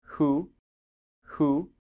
Произношение вопросительных слов — Американский вариант
Who — кто? [huː] — ху.
Who-audio-Amer.m4a